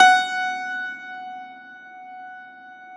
53e-pno16-F3.wav